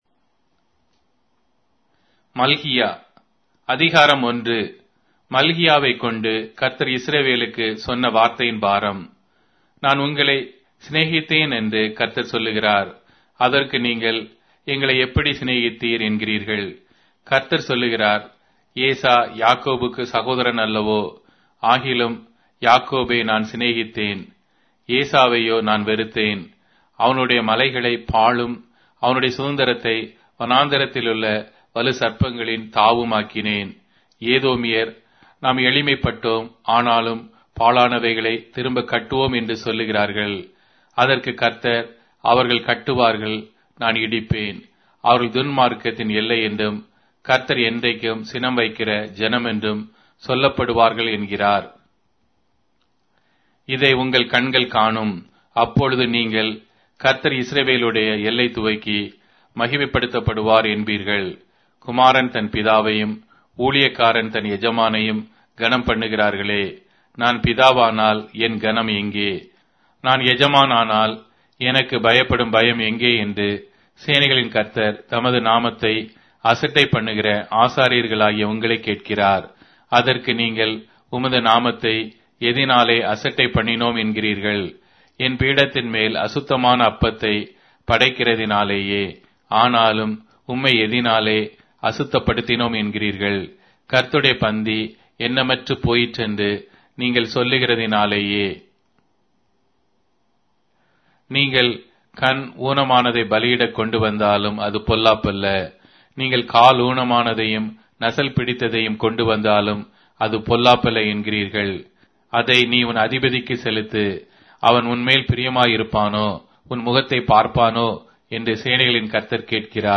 Tamil Audio Bible - Malachi 2 in Ervml bible version